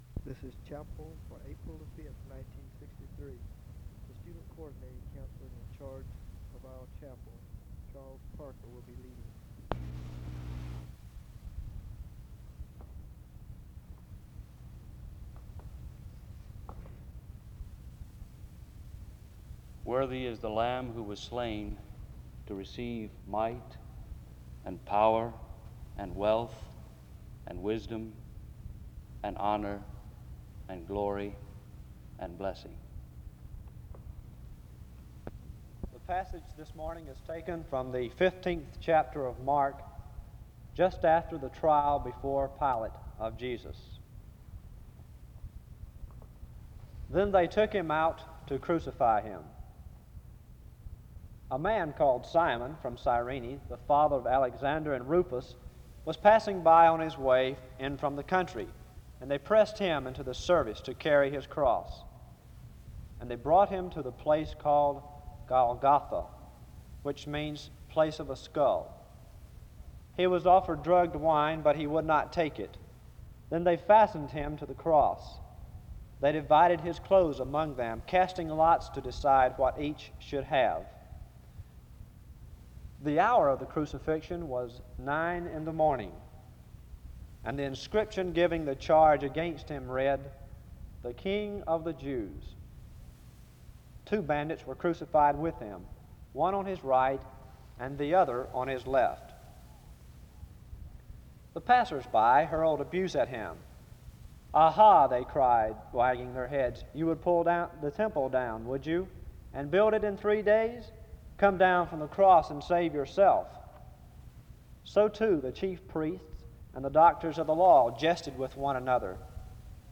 A student gives an opening word of prayer from 3:31-4:44.
There is a special song played from 17:47-20:45. This service was organized by the Student Coordinating Council.